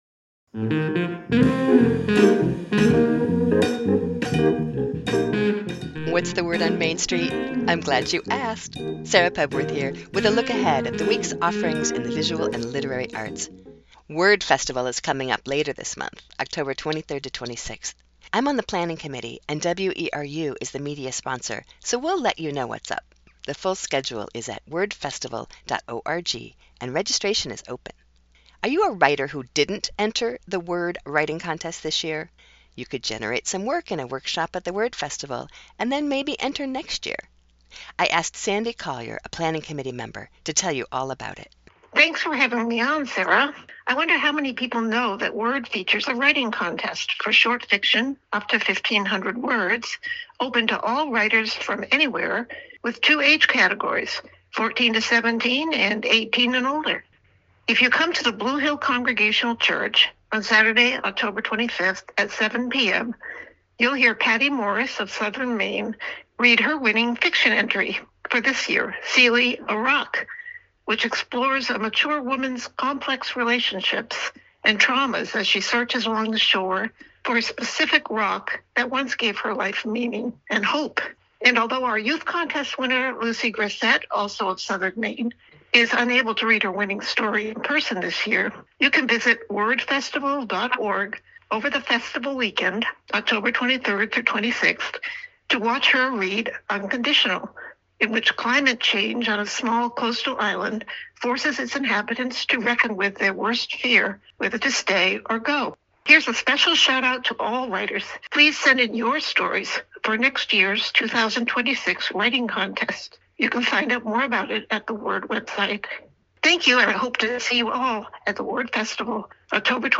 is a weekly short feature Saturdays at 9:30am looking at local literary and visual arts events and offerings!